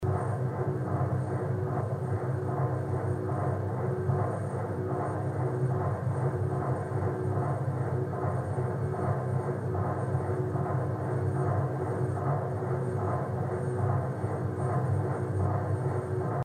normal noises of an MRI machine.
mrisounds.mp3